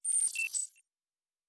Data Calculating 2_1.wav